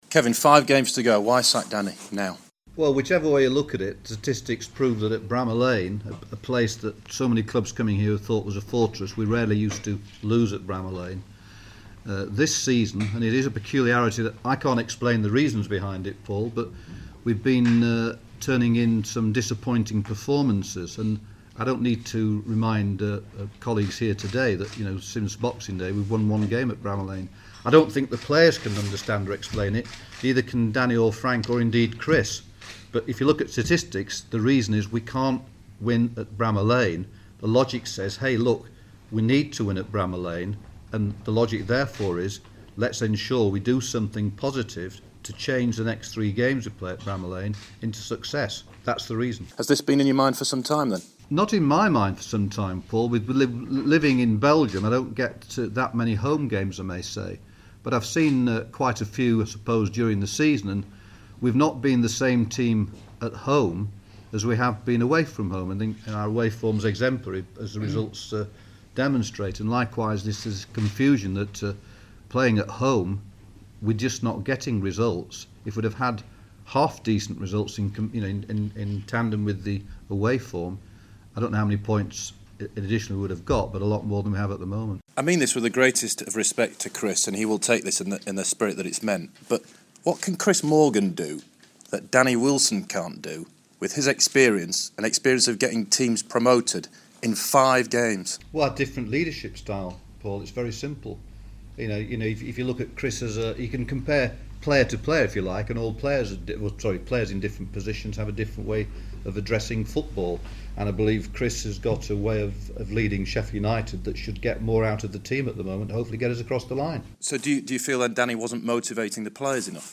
press conference highlights